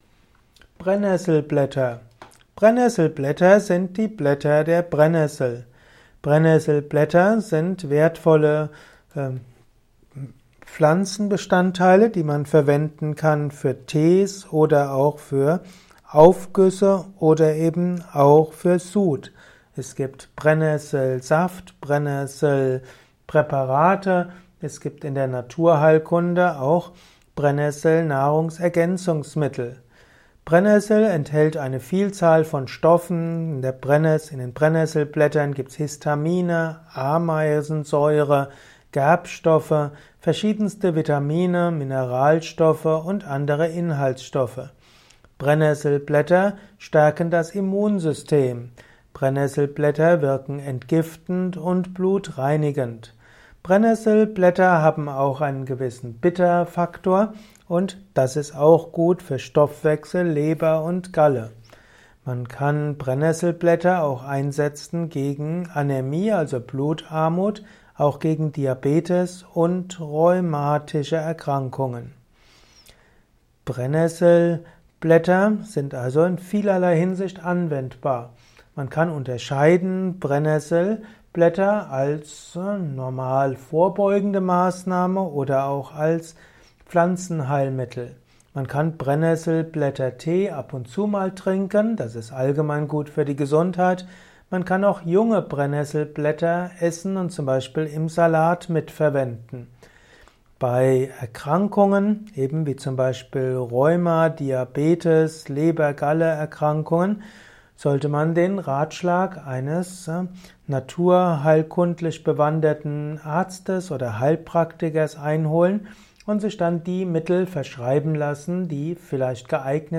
Kompakte Informationen zu Brennesselblättern in diesem Kurzvortrag